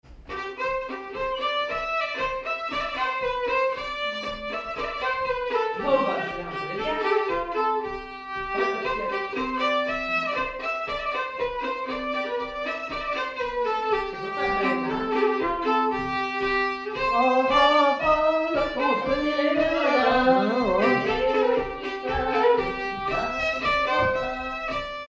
Danse
danse : polka
circonstance : bal, dancerie
Pièce musicale inédite